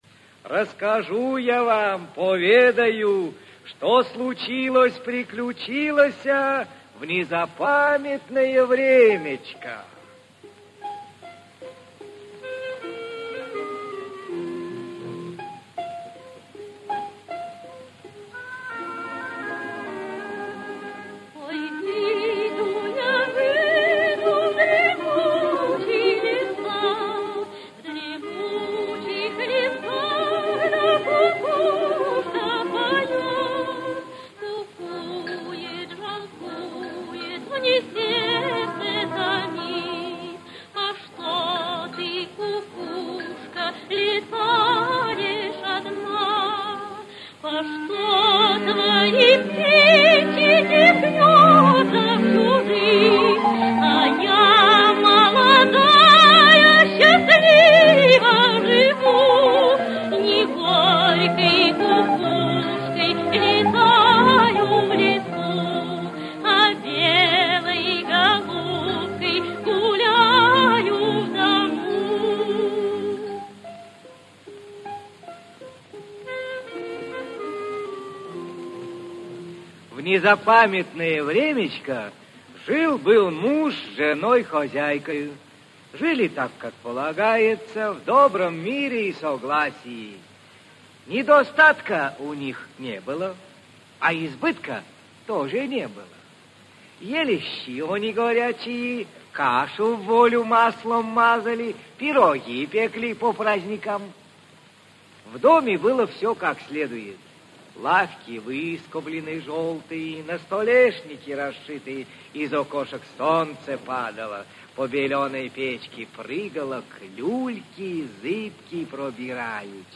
Аудиокнига Сестрица Аленушка и братец Иванушка (спектакль) | Библиотека аудиокниг
Aудиокнига Сестрица Аленушка и братец Иванушка (спектакль) Автор Народное творчество Читает аудиокнигу Актерский коллектив.